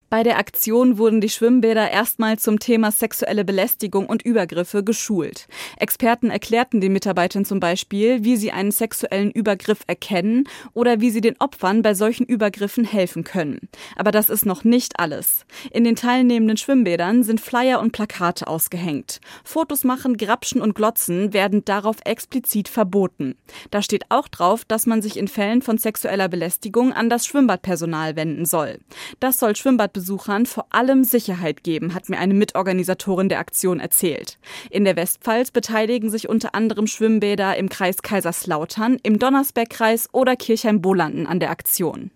Über dieses Thema berichtete das SWR Studio Kaiserslautern in den SWR4-Regionalnachrichten am 26.5.2025 um 6:30 Uhr.